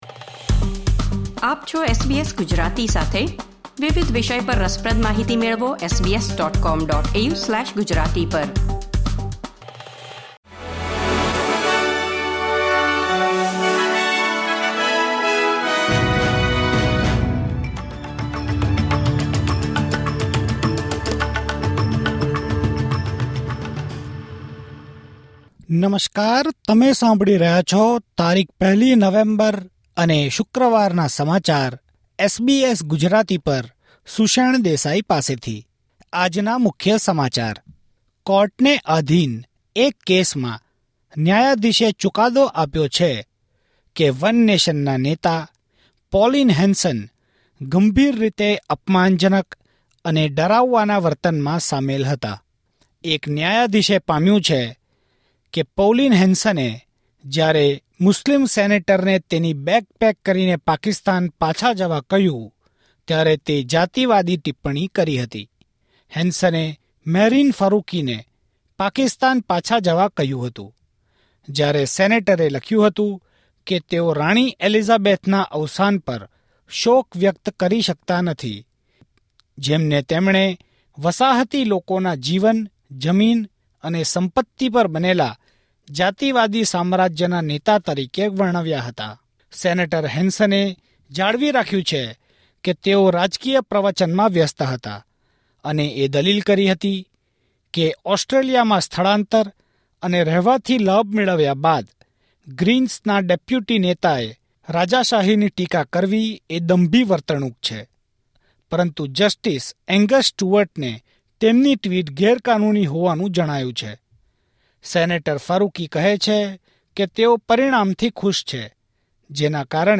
SBS Gujarati News Bulletin 1 November 2024